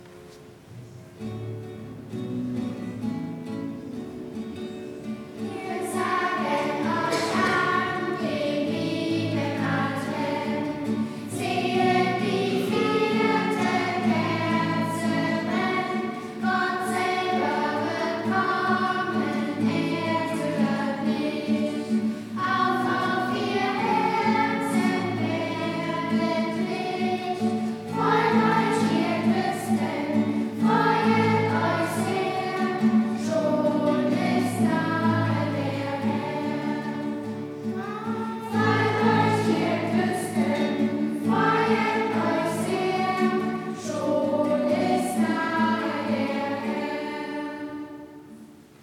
Wir sagen euch an den lieben Advent... Kinderchor der Ev.-Luth. St. Johannesgemeinde (Zwickau-Planitz) 0:49
Audiomitschnitt unseres Gottesdienstes vom 4.Advent 2025.